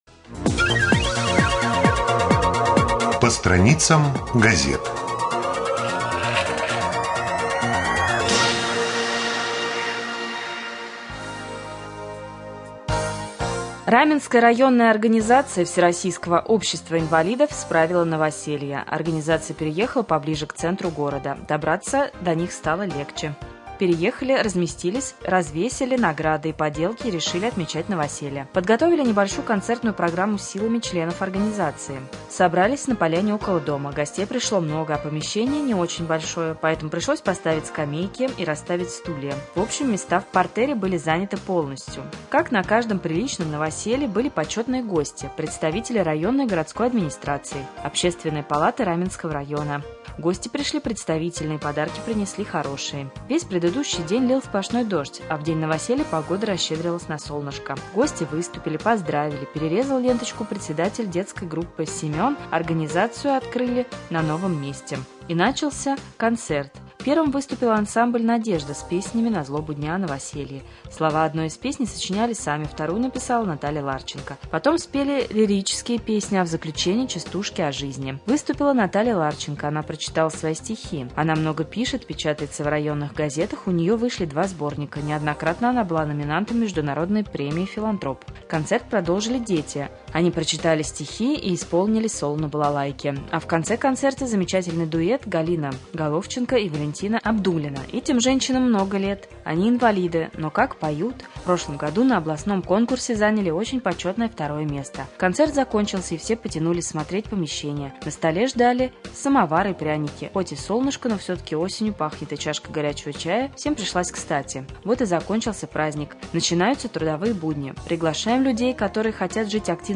2.Новости.mp3